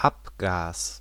Ääntäminen
Ääntäminen : IPA: [ˈʔap.ɡ] Tuntematon aksentti: IPA: [ˈapɡaːs] IPA: /ˈʔap.ɡas/ Haettu sana löytyi näillä lähdekielillä: saksa Käännös 1. escape {m} Artikkeli: das .